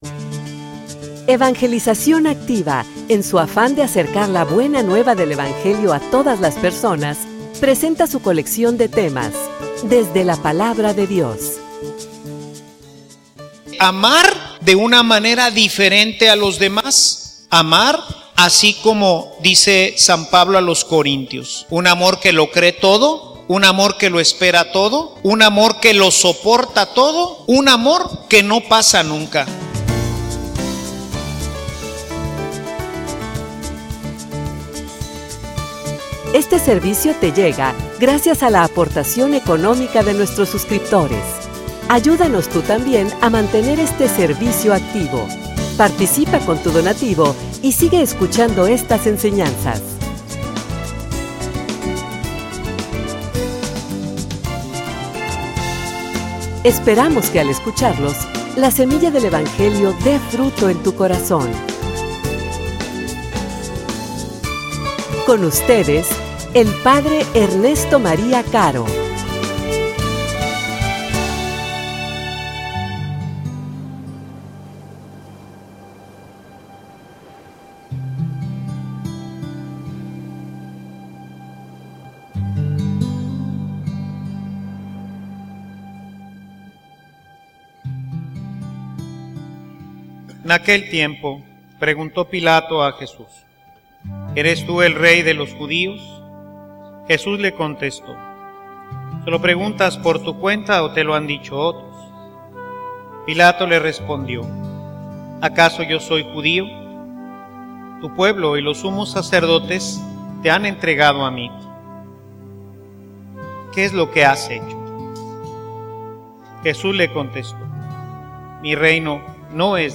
homilia_Un_reino_diferente.mp3